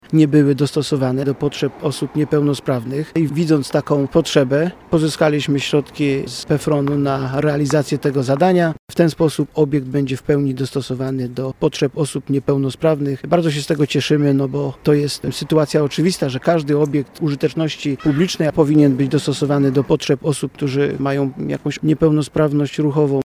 Mówi starosta niżański Robert Bednarz.